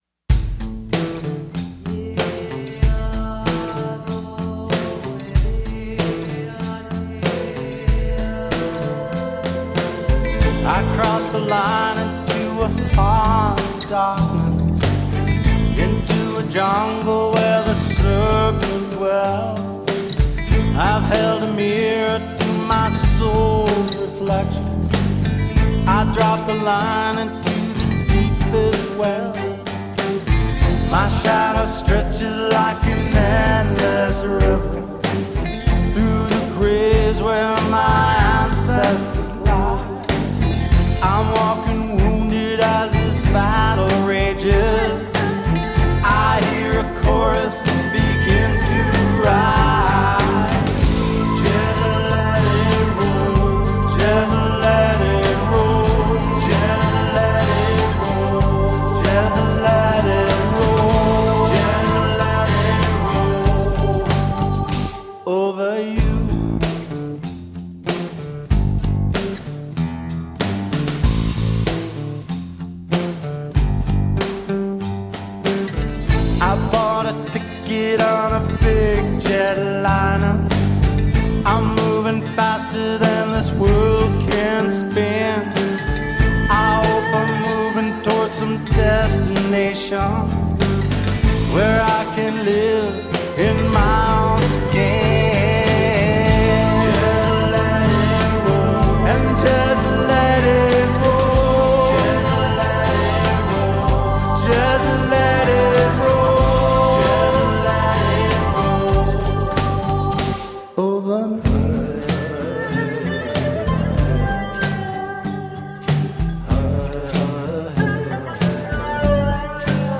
An introspective mix of acoustic guitar based songs.